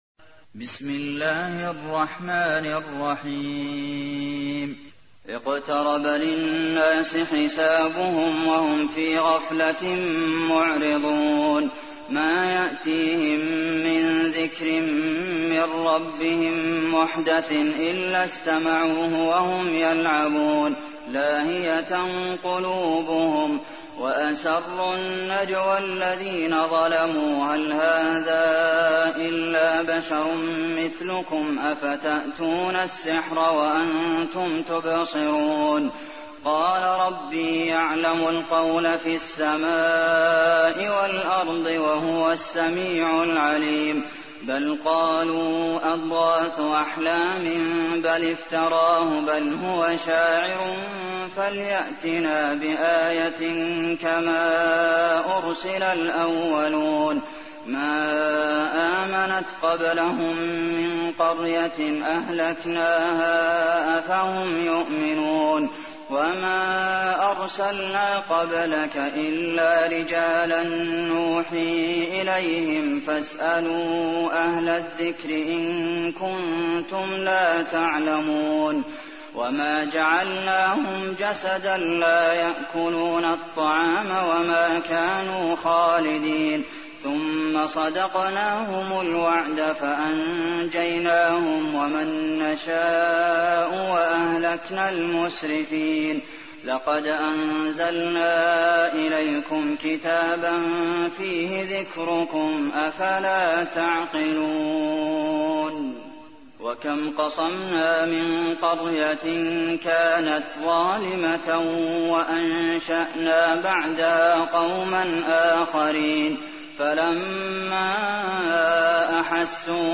سورة الأنبياء / القارئ عبد المحسن قاسم / القرآن الكريم / موقع يا حسين